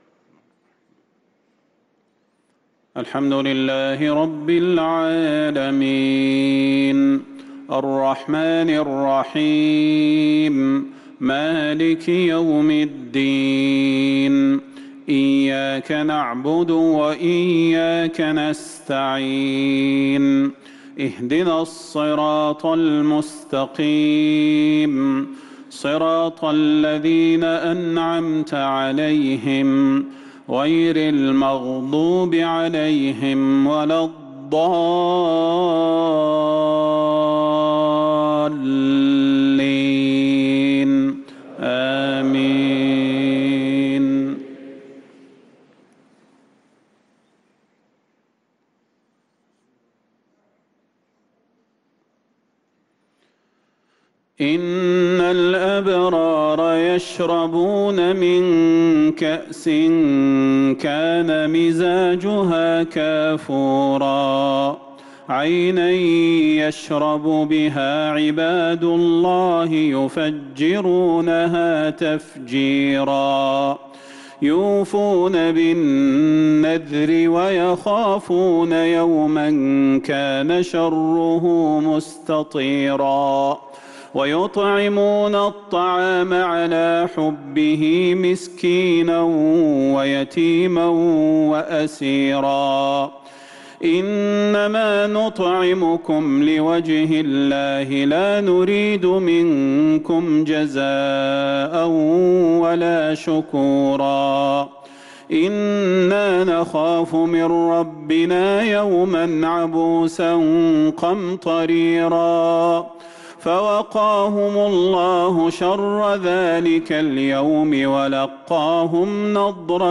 صلاة العشاء للقارئ صلاح البدير 10 ربيع الآخر 1445 هـ
تِلَاوَات الْحَرَمَيْن .